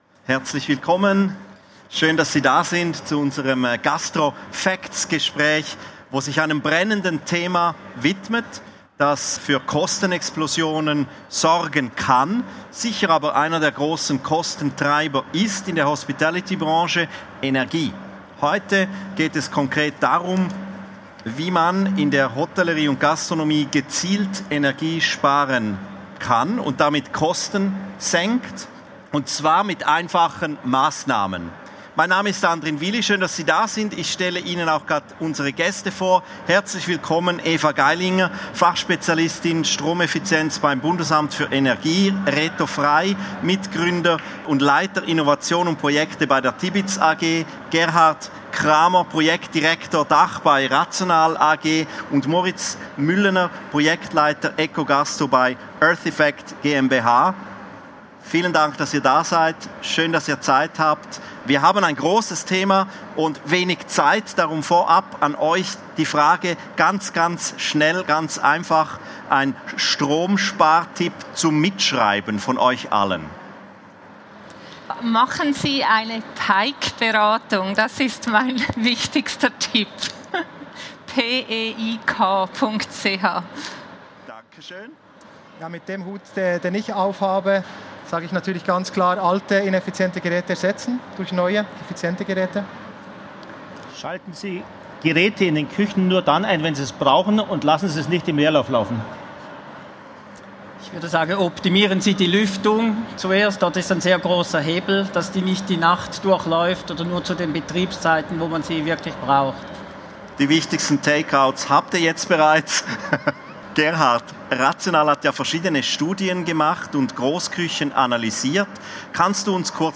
Das Podiumsgespräch fand am 17. November 2025 auf der Bühne der Cheminée Chats der Igeho 2025 statt.